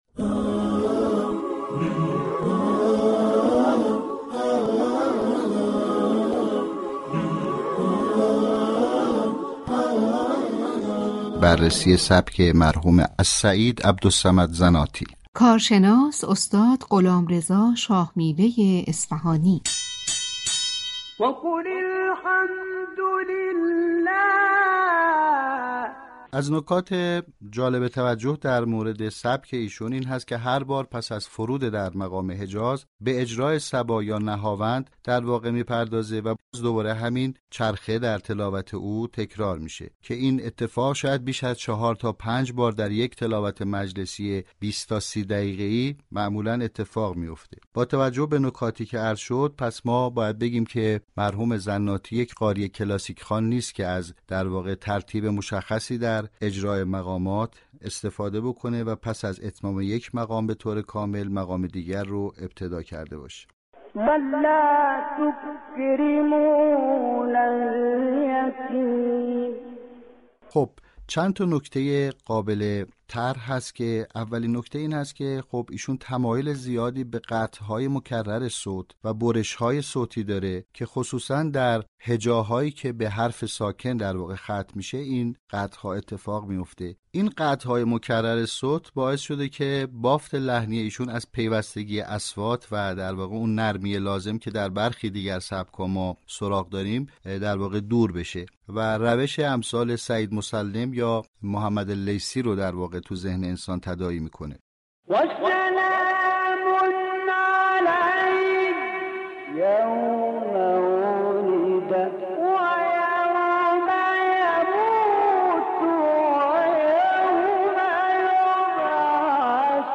كارشناس برنامه همچنین با اشاره به تمایل زناتی به استفاده از قطعات مكرر صوت، به‌ویژه در هجاهای ختم‌شده به حرف ساكن، افزود: این قطع‌های مكرر، بافت لحنی را از پیوستگی و نرمی سبك‌های دیگر دور ساخته و یادآور روش قاریانی چون سعید مسلم و محمد لیثی است.
این تحلیل كه با استناد به نمونه‌های متعدد از تلاوت‌های زناتی ارائه شد، جلوه‌ای تازه از تنوع و ابتكار در سبك‌های تلاوت قرآن كریم را آشكار ساخت.